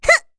Rehartna-Vox_Attack5_kr.wav